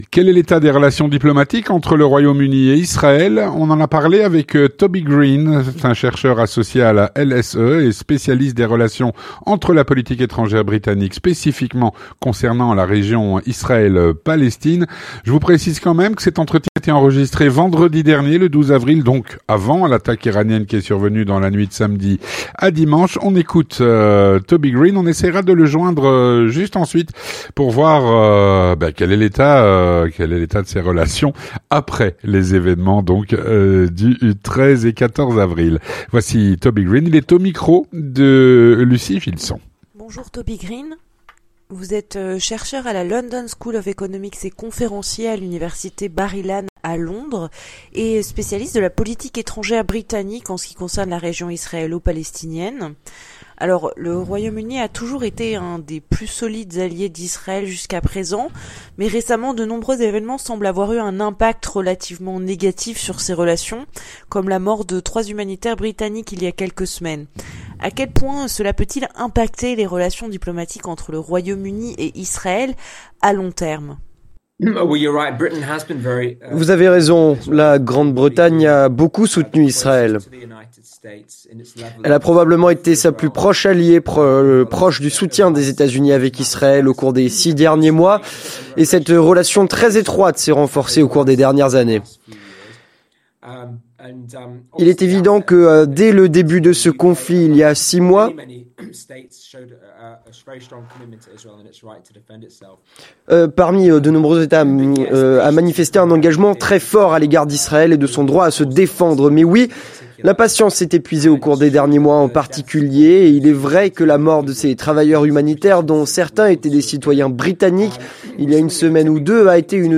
NB : cet entretien a été enregistré le Vendredi 12 Avril, soit avant l’attaque iranienne qui est survenue dans la nuit de samedi à dimanche.